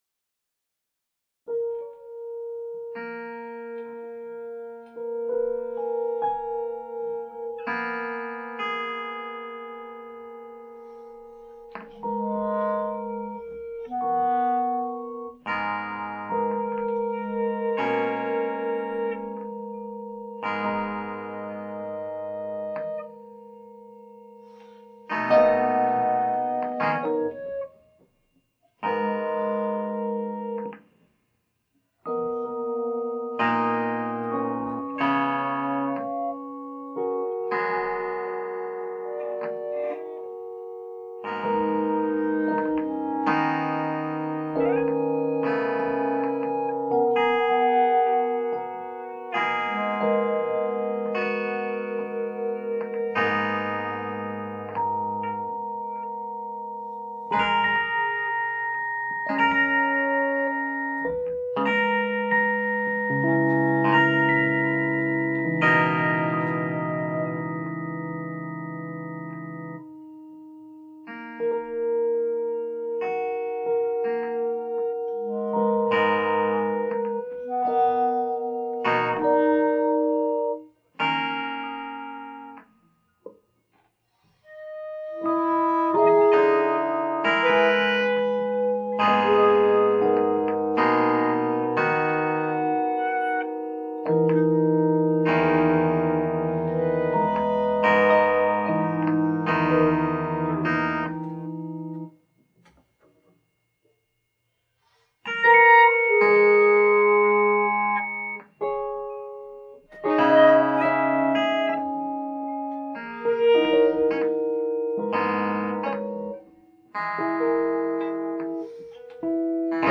Why does this music seem so sad?